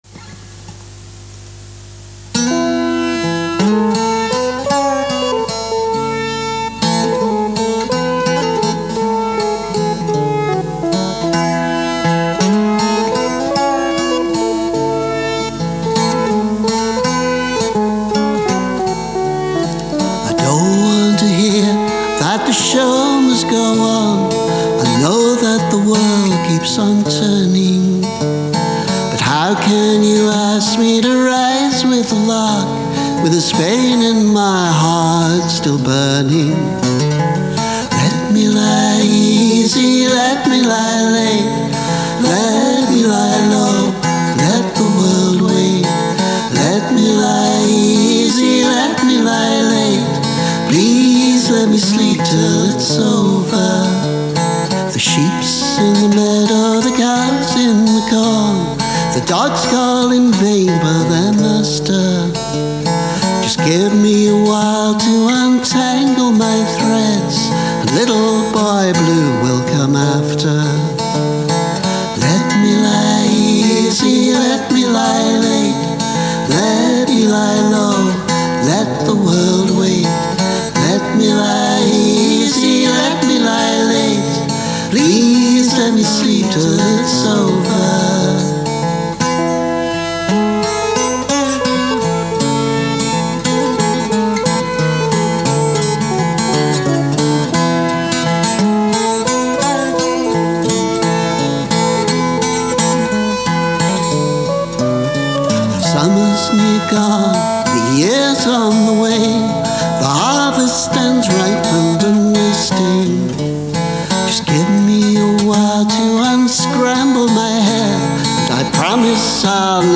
Re-remaster:
Guitar, multitracked vocals, synth. All me…